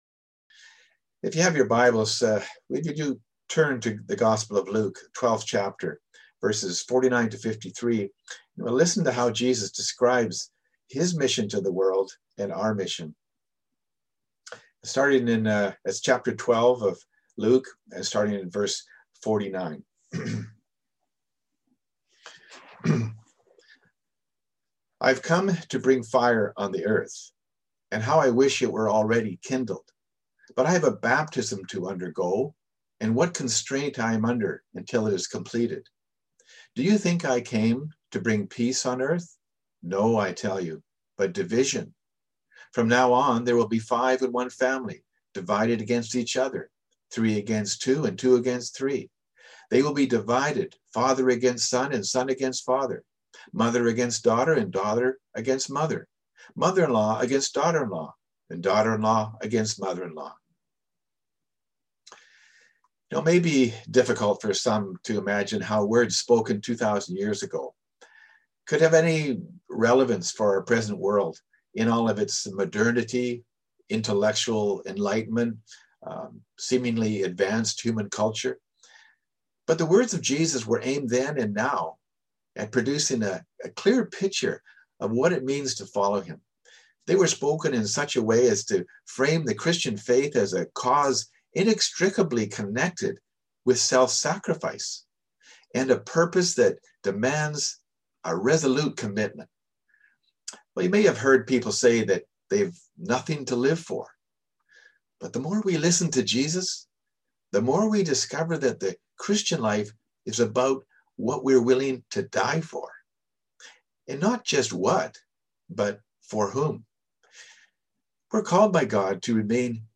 Sermons | Peninsula Mission Community Church